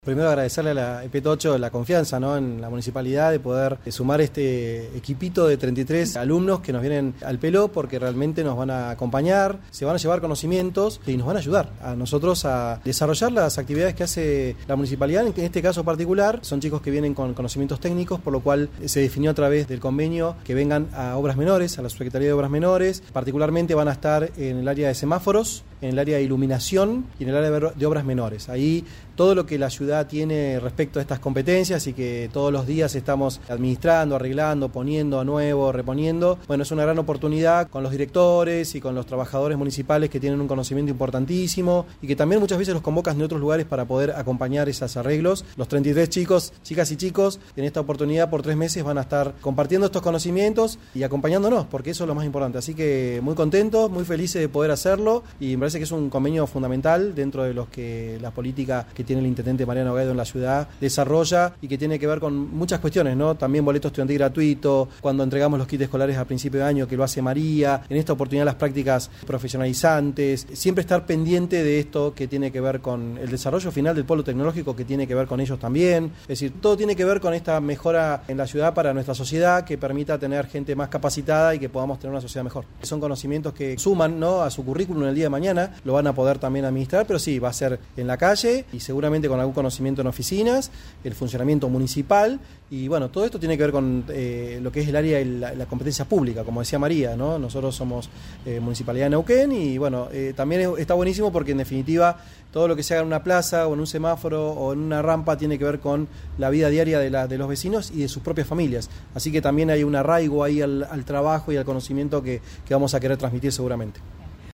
Santiago Morán, secretario de Movilidad y Servicios al Ciudadano.